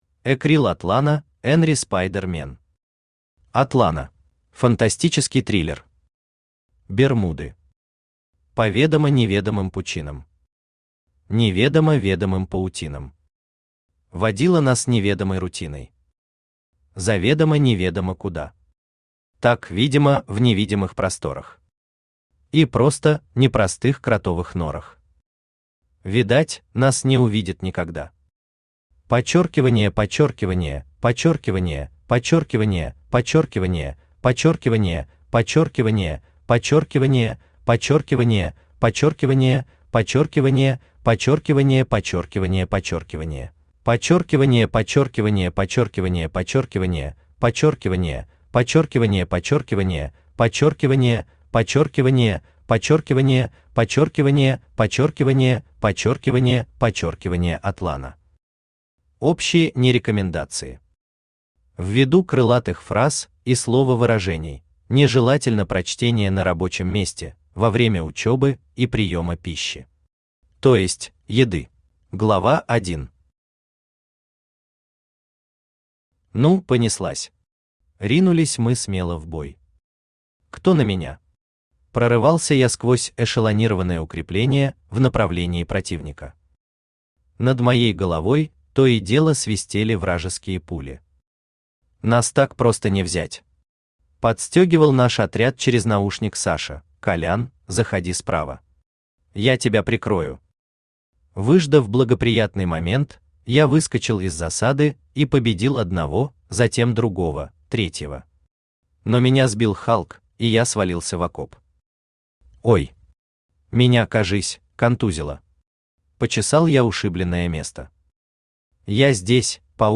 Aудиокнига Атлана Автор Экрил Атлана Читает аудиокнигу Авточтец ЛитРес.